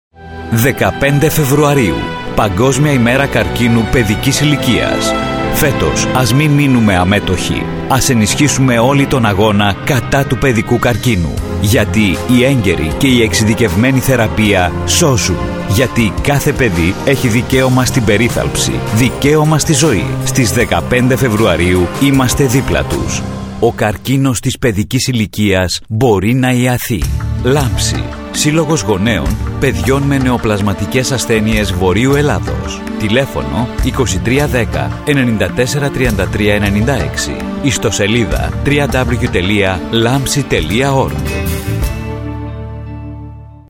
Για το λόγο αυτό εγκρίθηκε από το ΕΣΡ και σχετικό ραδιοφωνικό & τηλεοπτικό μήνυμα.
ΛΑΜΨΗ-ΡΑΔΙΟΦΩΝΙΚΟ-ΣΠΟT.mp3